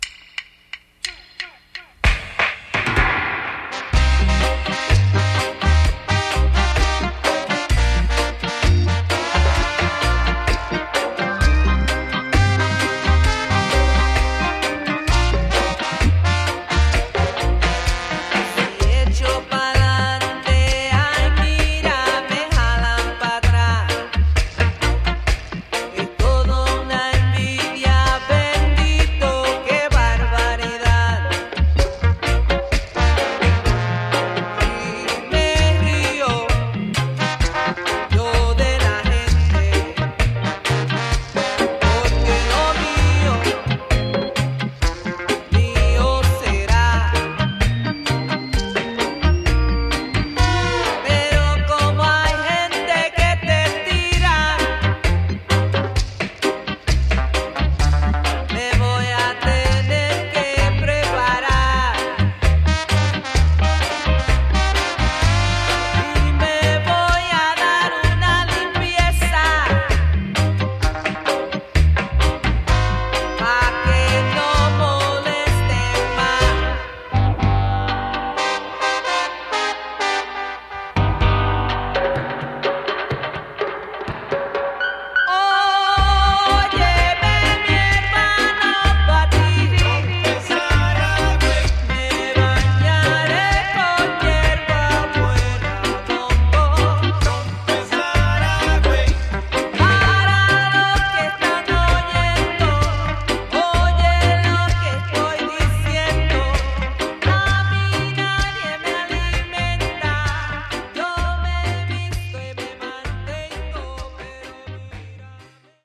Tags: Reggae , Salsa